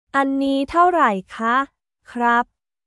アンニー タオライ カ／クラップ